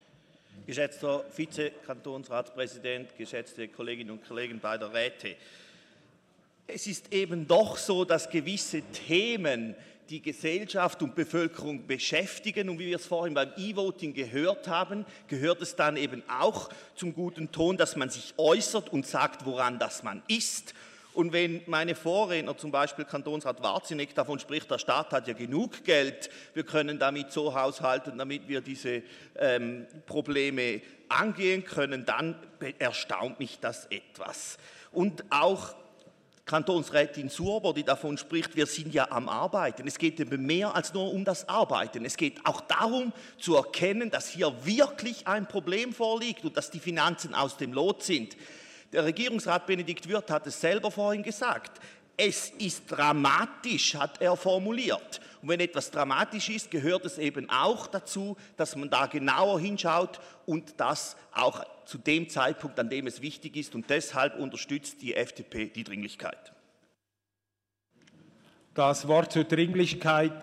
Session des Kantonsrates vom 23. und 24. April 2019